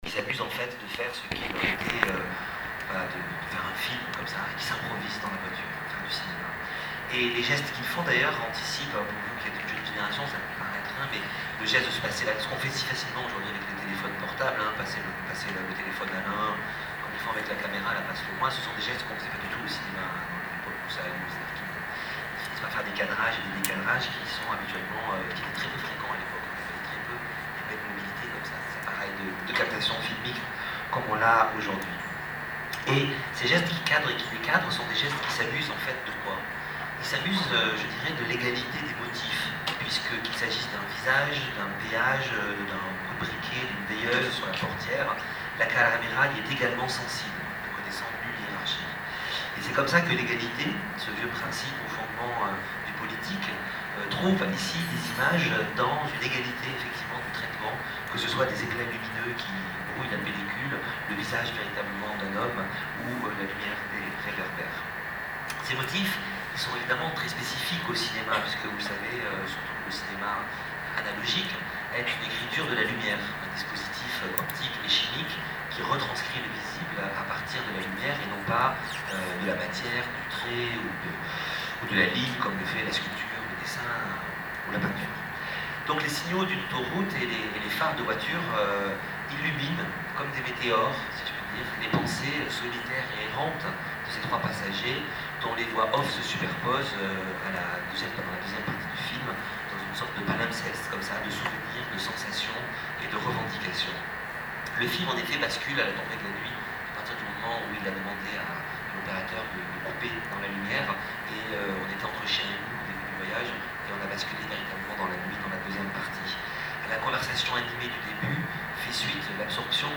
Lieu : école d’art de Belfort